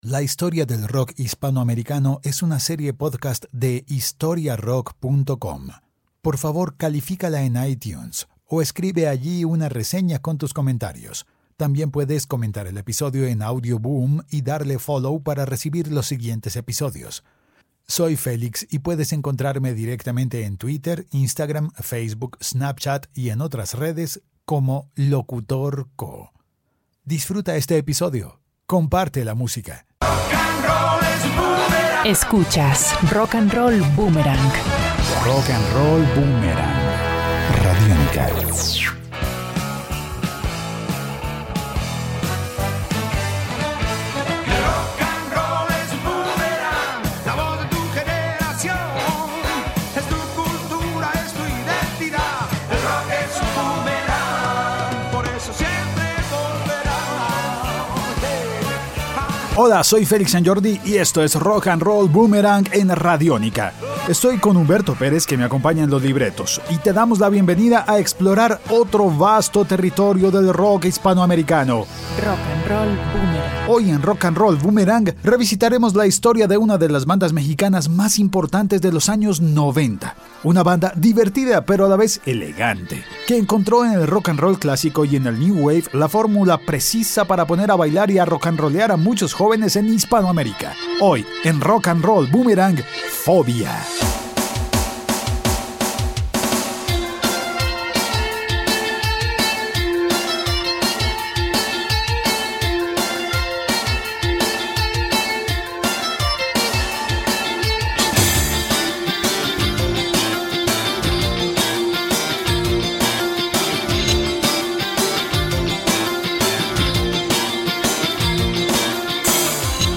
Canción 1: La iguana Canción 2: El crucifijo